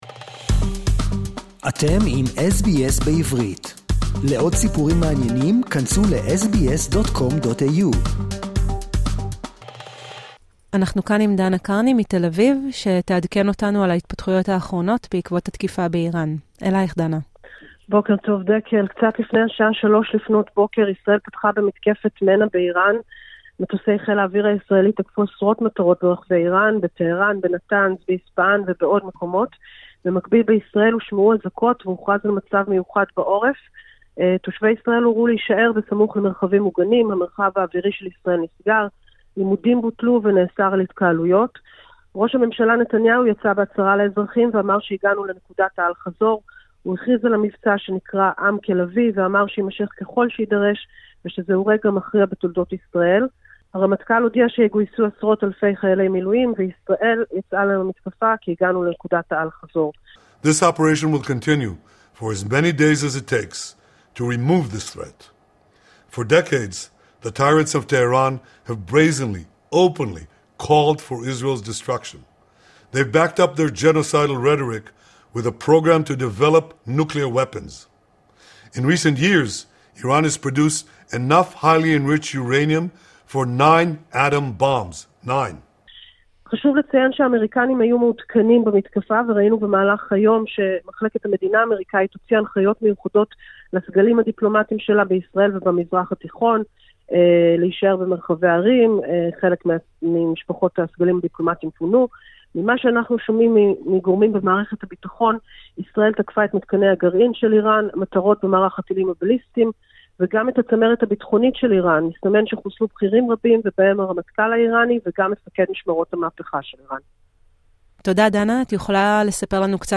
עדכון חדשות בעקבות התקיפה באיראן